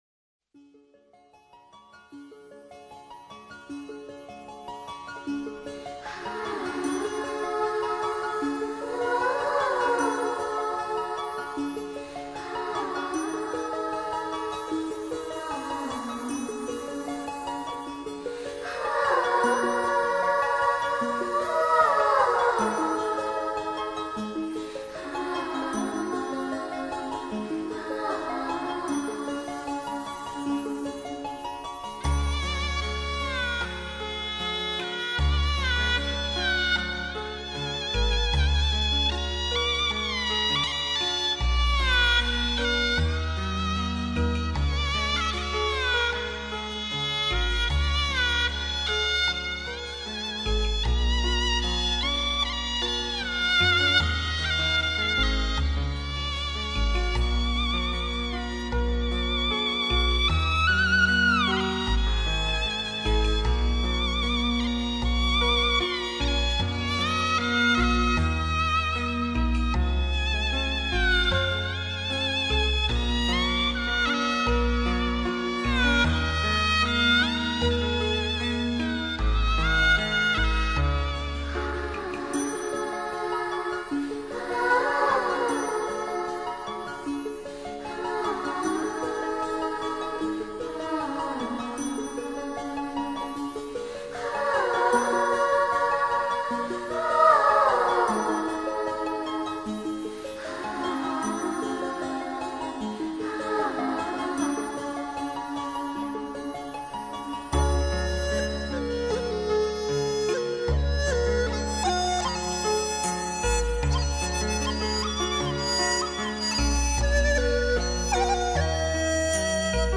最優質的東方健康音樂
先以流水般輕盈的古箏、二胡，為您滌淨一切煩擾
再以充滿靈氣的笛、簫、琵琶，涵養您的心神
然後以符合母體律動的竹笛、二胡，溫暖、放鬆您的心神
輕鬆、舒適、恬靜的音樂風格，結合各種對